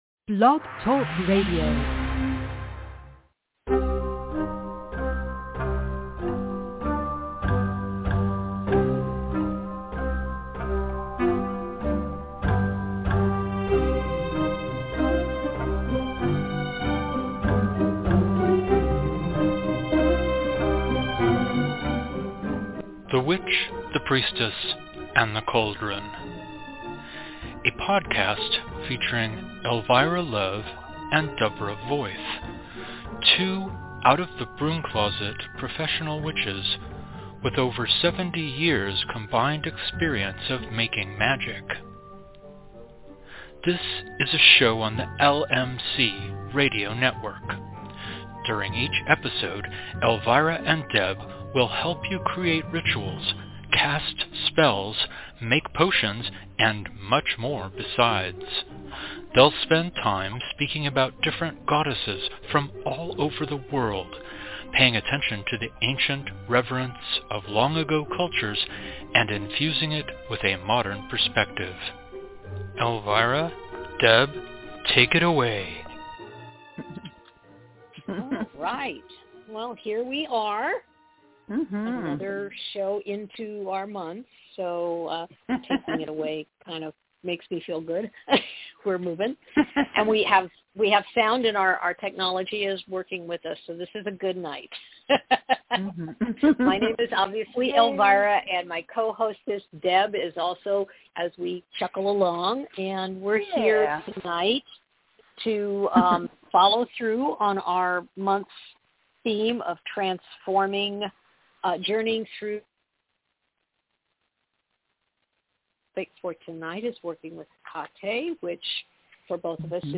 A new episode of The Witch, The Priestess, and The Cauldron air LIVE every Thursday at 4pm PT / 7pm ET!